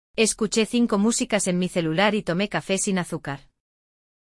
S, Z e C (em CE e CI) têm som de /θ/ (como um S com a ponta da língua entre os dentes).
➡ O ceceo é comum em algumas regiões da Espanha.